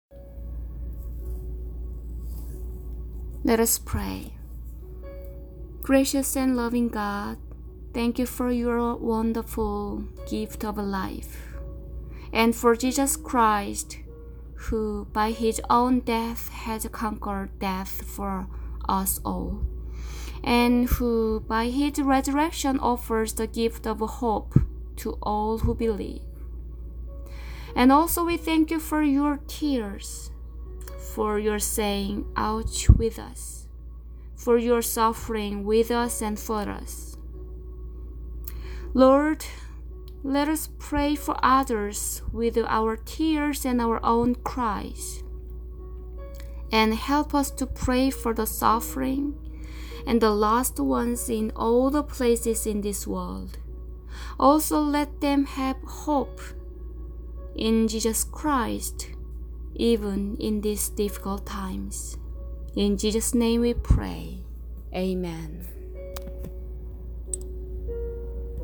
Closing Prayer for March 29, 2020